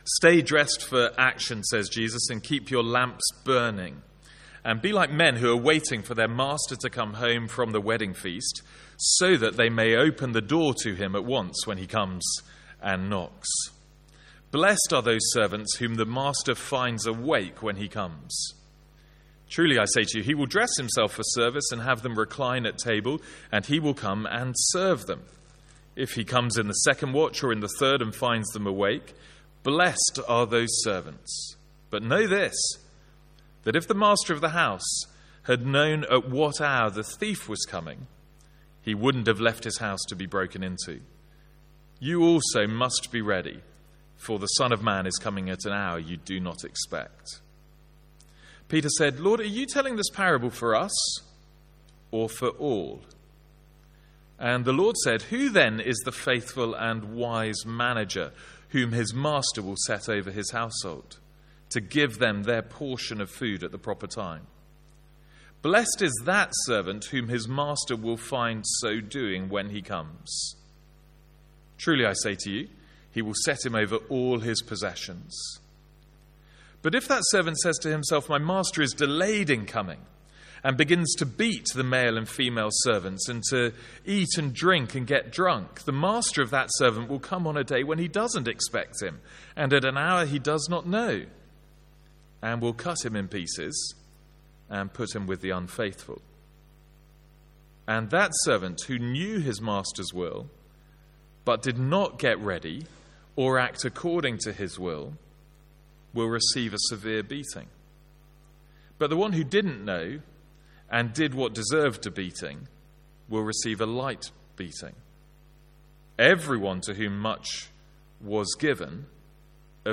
Sermons | St Andrews Free Church
From our evening series in Luke.